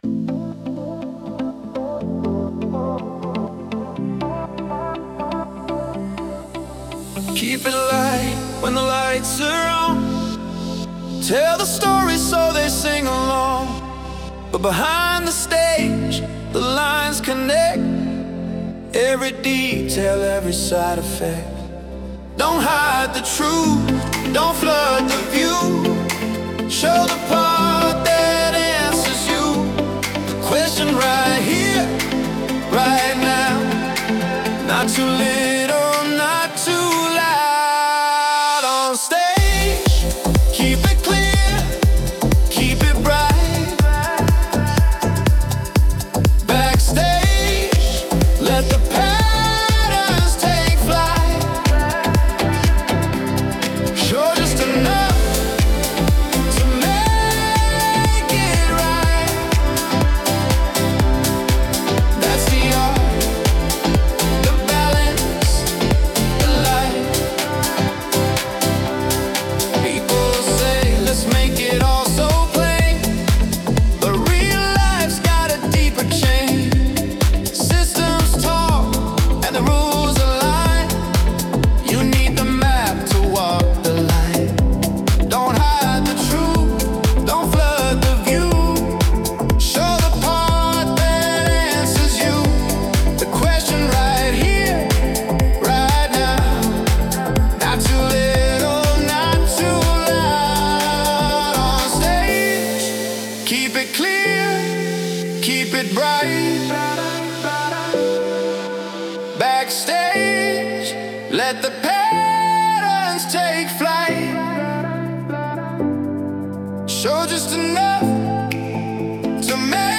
Tropical House · 122 BPM · Eng